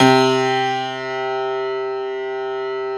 53f-pno06-C1.aif